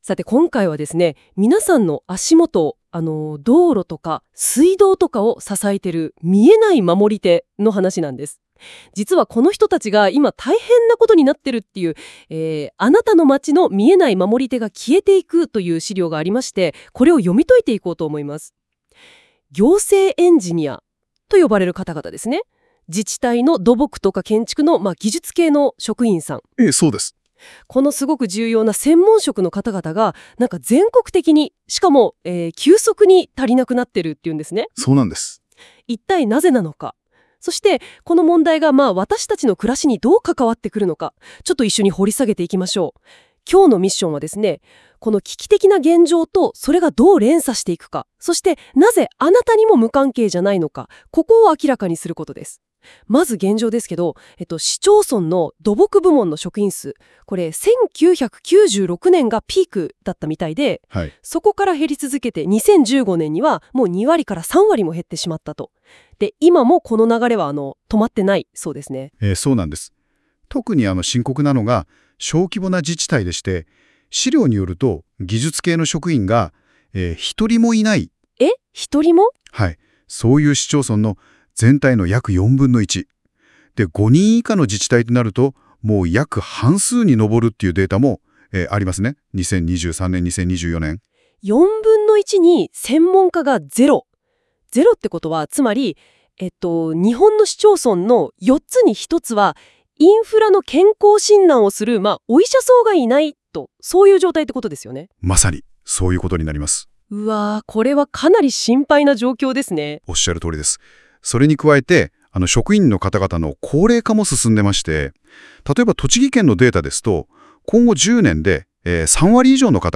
AIによる音声要約 ver.2